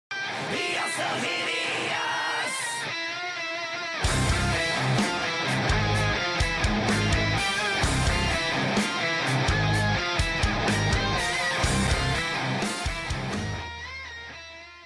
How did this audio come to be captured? I messed up the audio a little but idk how to fix it :(